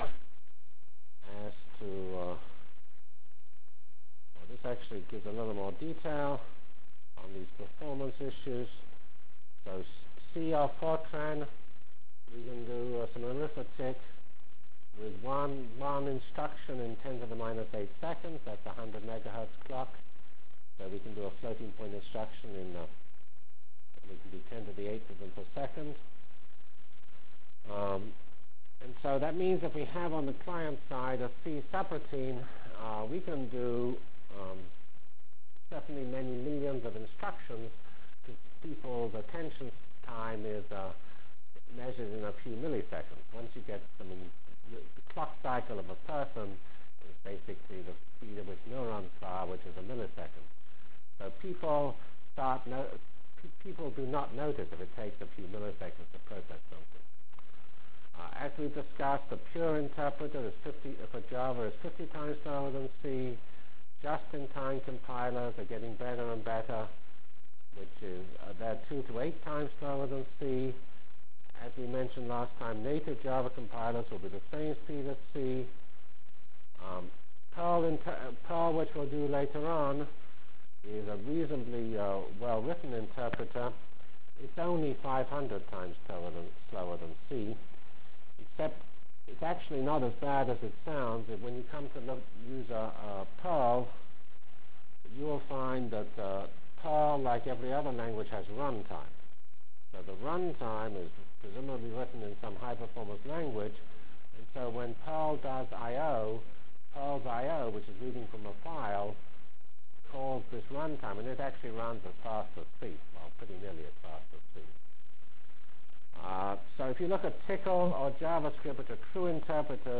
From Feb 10 Delivered Lecture for Course CPS616 -- Introduction to JavaScript CPS616 spring 1997 -- Feb 10 1997.